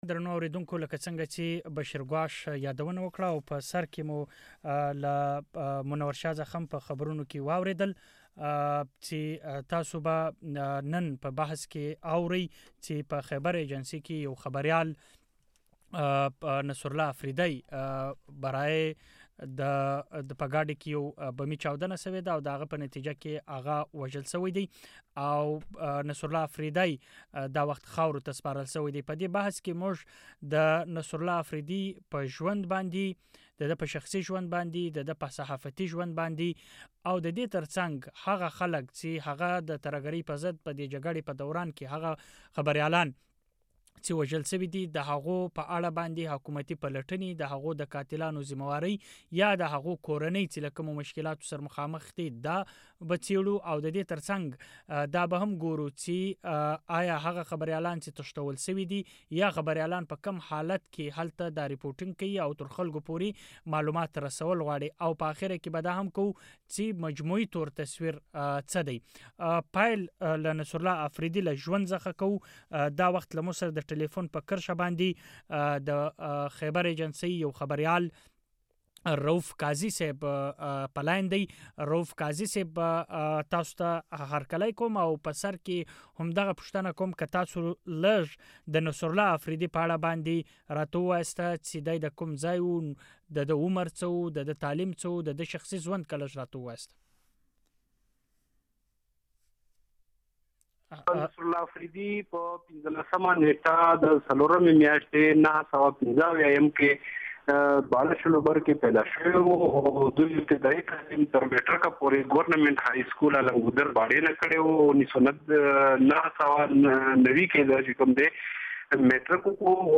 پوښتنه دا ده چې څوک دا خبریالان وژني،په اړه یې حکومتي پلټنې کوم ځای ته رسېدلې دي.دغه وژنو د خبریالانو د لیکنو او وینا آزادي ته څومره زیان رسولی. د مشال تر رڼا لاندې بحث هم دې موضوع ته ځانګړی دی